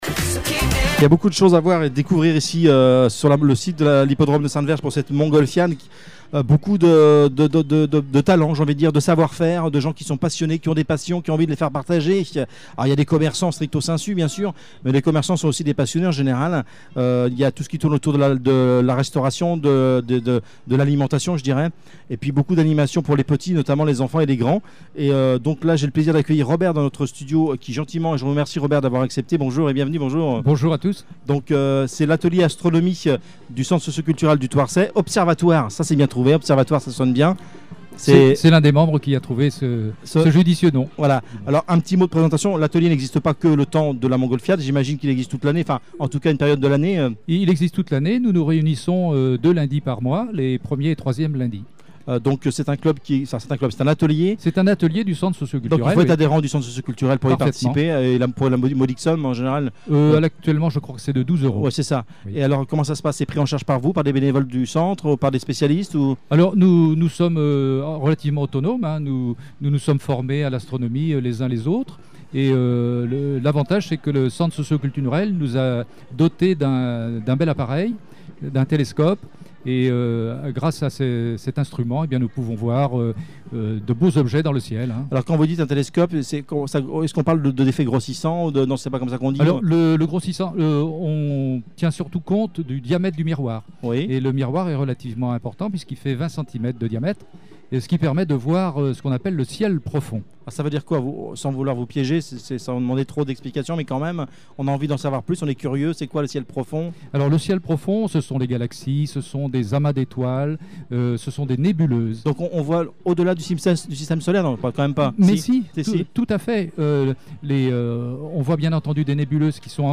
au micro de Radio Val d'Or